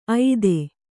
♪ aide